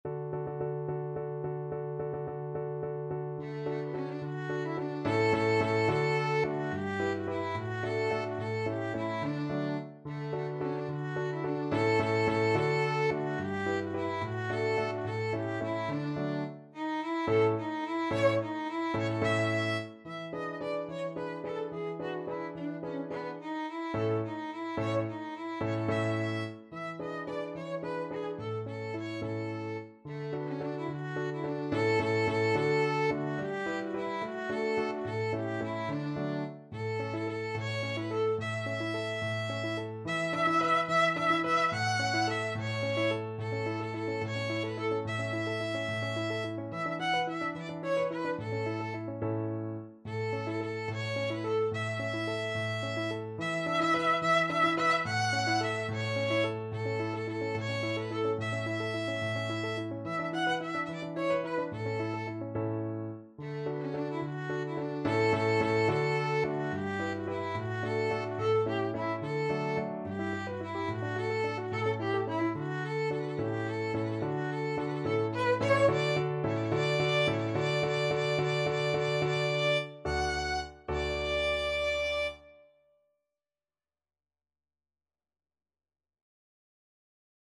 Violin
3/8 (View more 3/8 Music)
D major (Sounding Pitch) (View more D major Music for Violin )
Traditional (View more Traditional Violin Music)